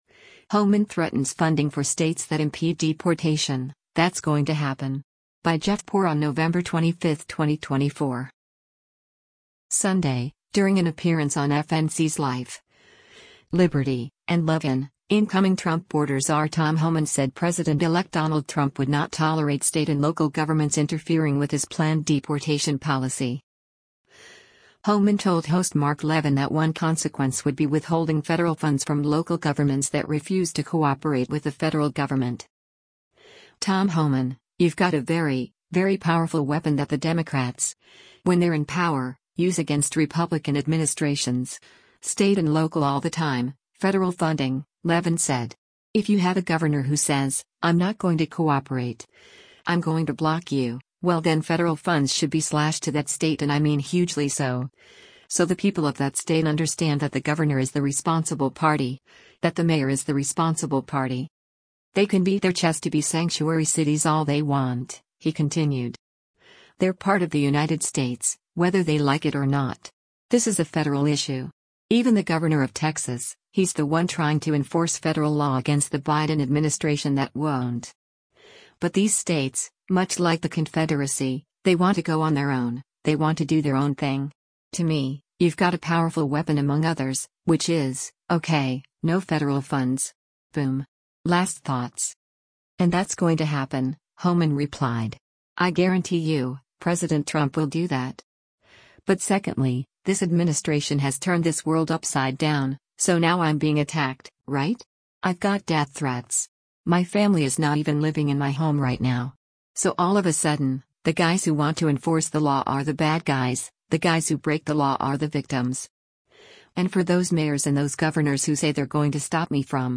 Sunday, during an appearance on FNC’s “Life, Liberty & Levin,” incoming Trump border czar Tom Homan said President-elect Donald Trump would not tolerate state and local governments interfering with his planned deportation policy.
Homan told host Mark Levin that one consequence would be withholding federal funds from local governments that refuse to cooperate with the federal government.